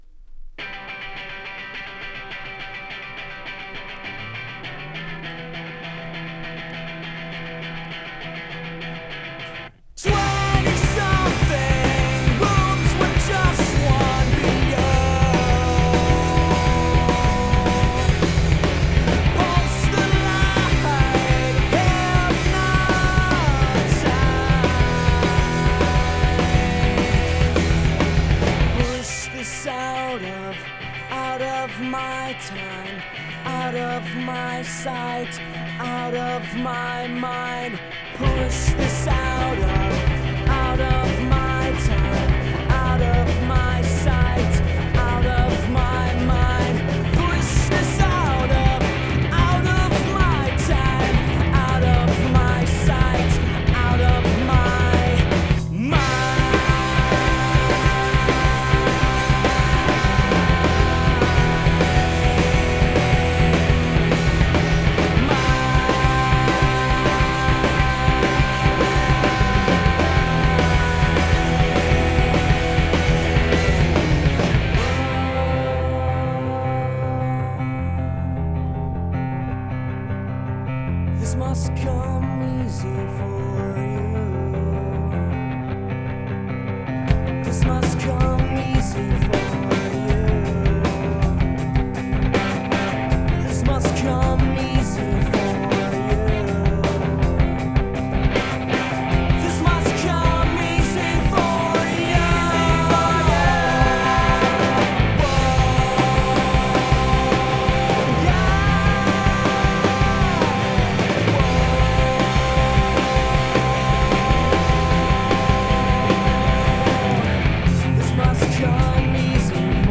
Guitar/Vocals
Drums
Bass Filed under: Emo